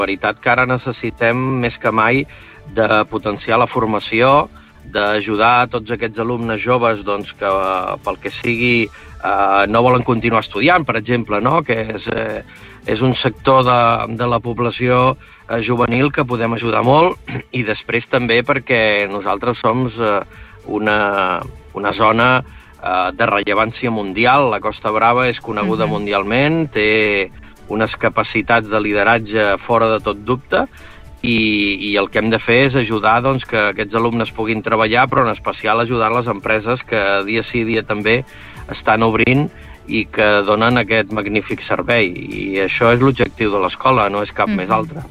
Entrevistes Supermatí